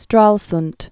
(strälsnt, shträlznt)